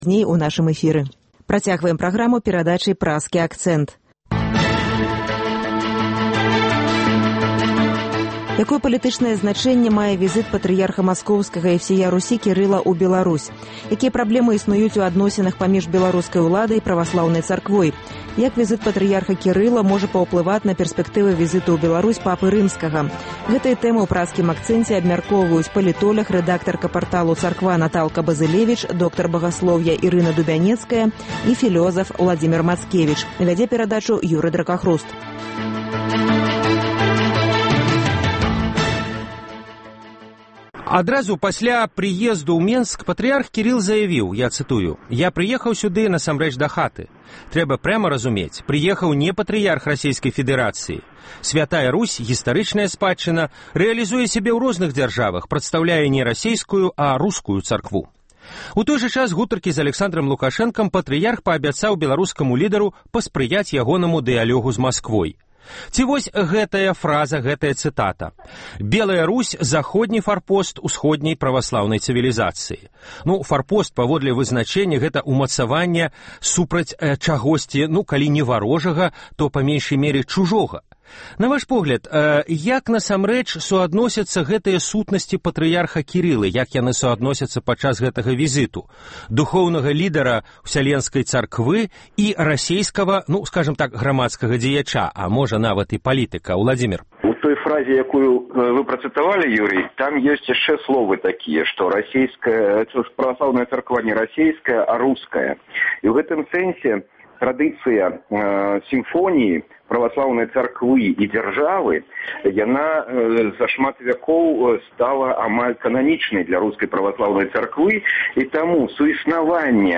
Гэтыя тэмы ў “Праскім акцэнце” абмяркоўваюць : палітоляг